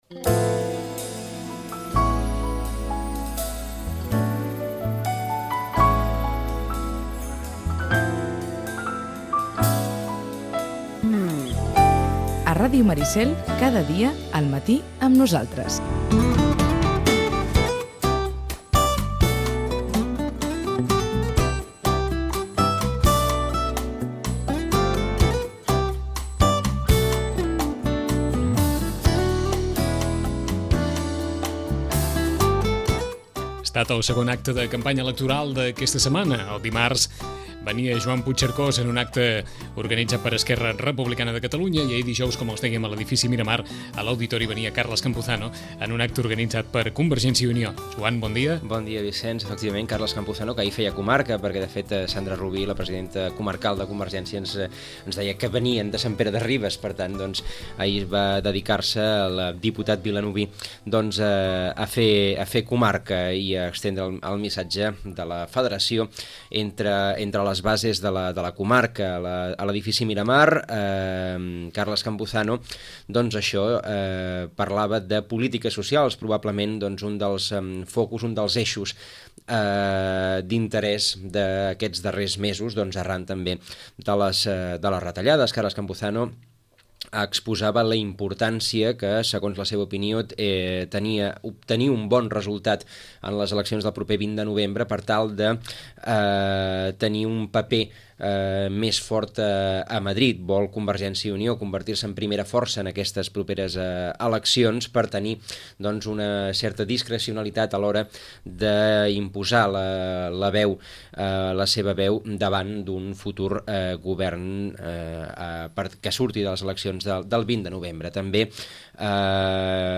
El diputat de CiU, Carles Campuzano, pronuncià ahir la conferència Polítiques socials en temps de crisi, en un acte organitzat per la secció local de la federació. Abans de començar l’acte, resumí el que enten que ha de ser el paper de CiU a Madrid.